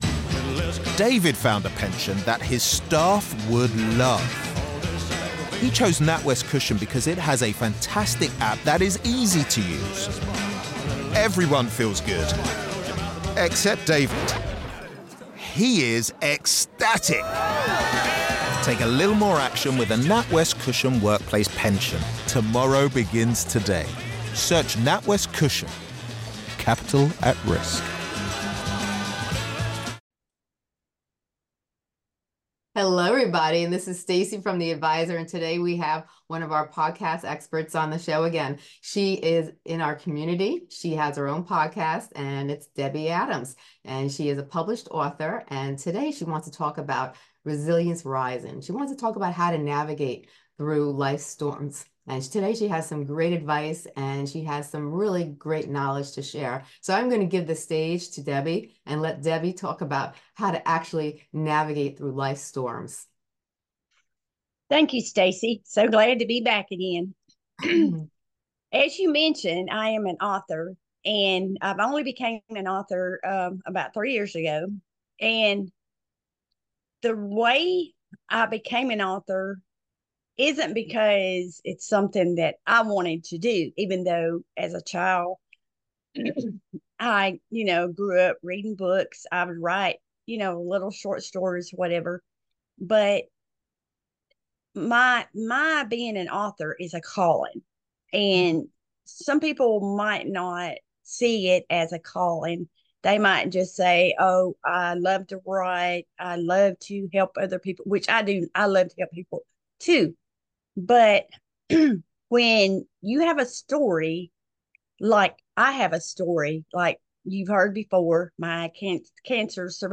Join them as they explore practical strategies for overcoming life's storms and emerging stronger. If you're seeking inspiration and empowerment to navigate through challenges, this conversation is a must-listen.